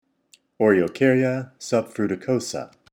Pronunciation/Pronunciación:
O-re-o-cár-ya suf-fru-ti-cò-sa